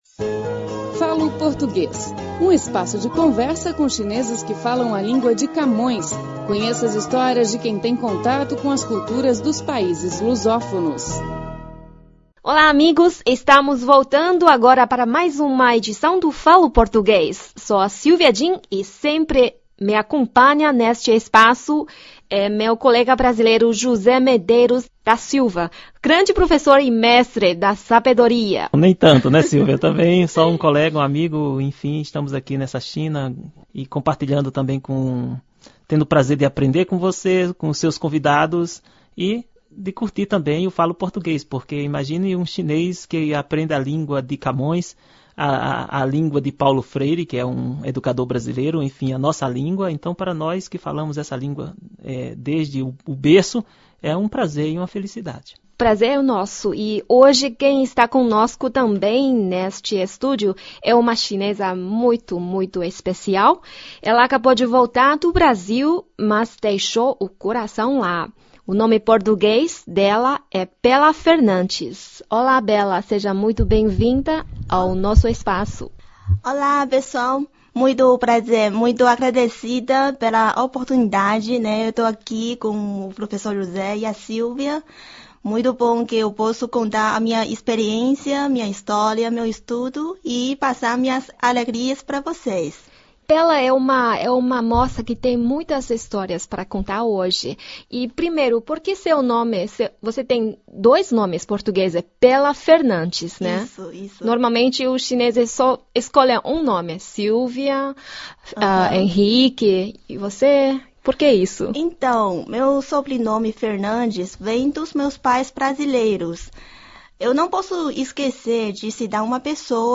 Falo Português: Entrevista